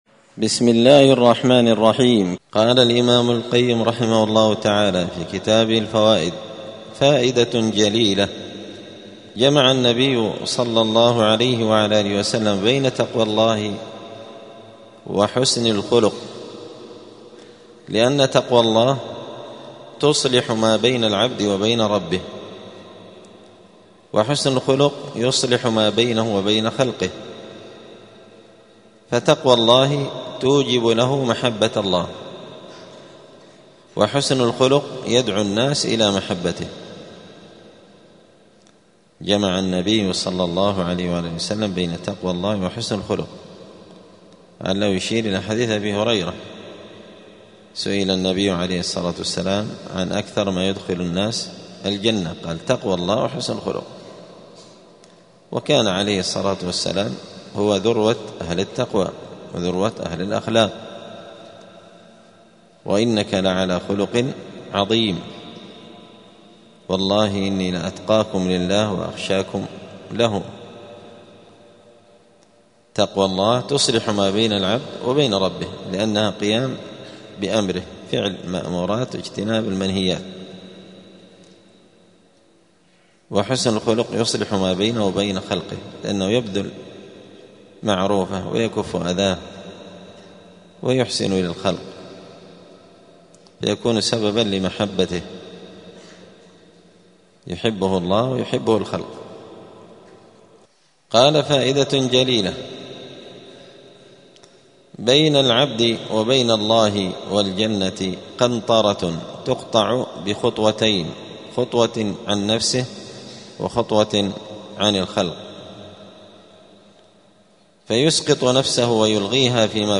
*الدرس التاسع والعشرون (29) {فصل: الجمع بين التقوى وحسن الخلق}*
دار الحديث السلفية بمسجد الفرقان قشن المهرة اليمن